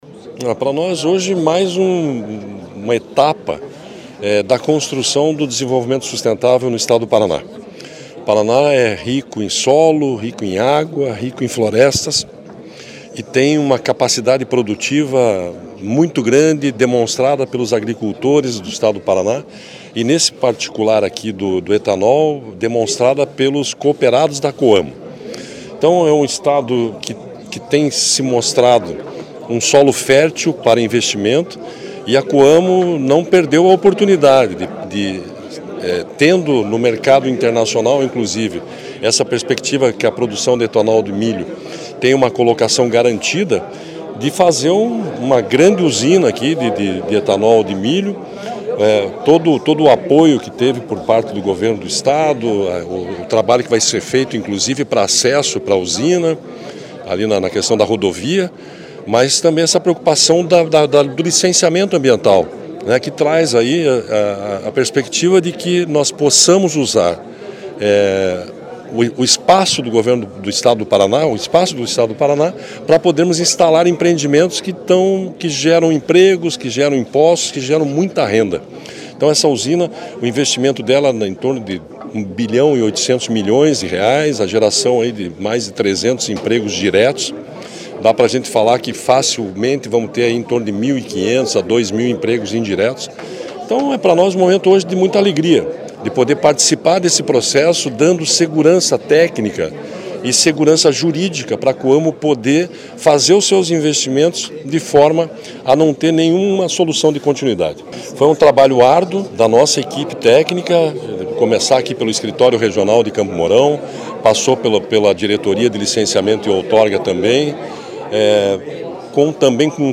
Sonora do diretor-presidente do IAT, Everton Souza, sobre a entrega de licença para a fábrica de etanol de milho da Coamo